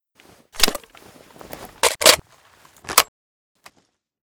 thompson_reload_drum.ogg